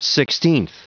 Prononciation du mot sixteenth en anglais (fichier audio)
Prononciation du mot : sixteenth